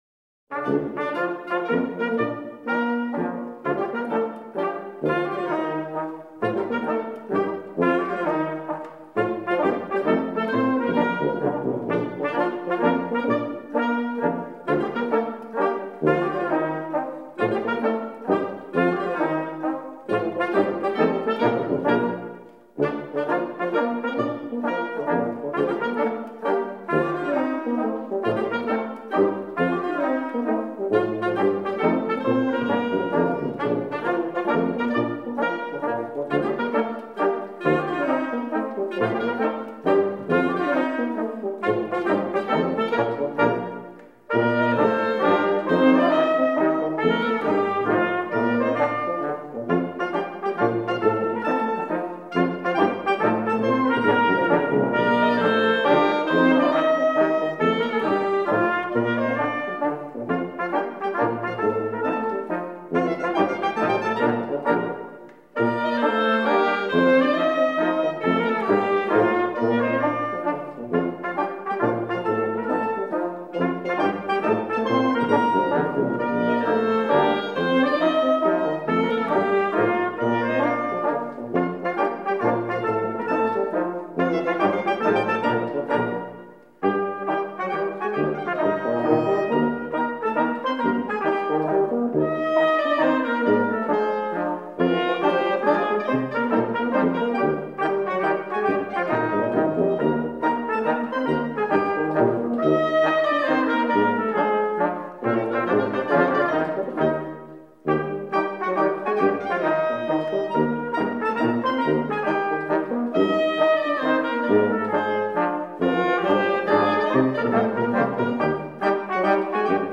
Ticino. Trad., arr. Bandella Tremonese